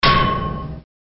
Download Free Hit Bar Half Life Sound Effects
Hit Bar Half Life